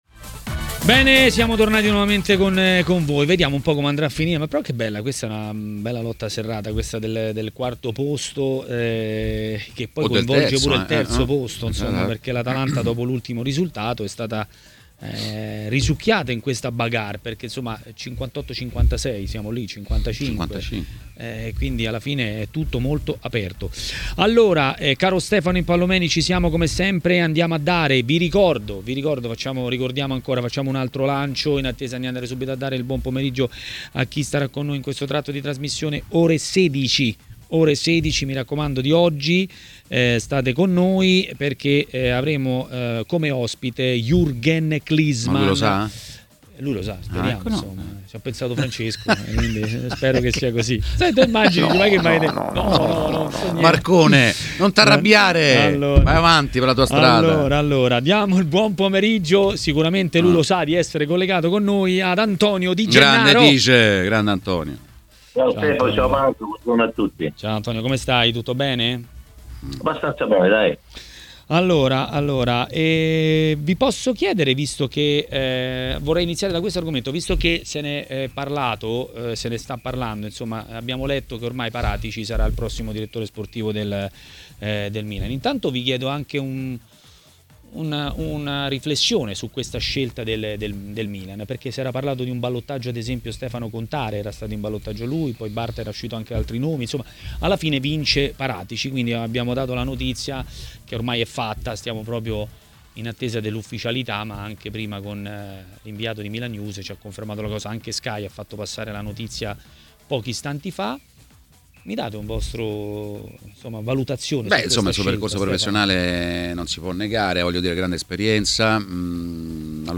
L'ex calciatore e dirigente Massimo Taibi è intervenuto ai microfoni di TMW Radio, durante Maracanà.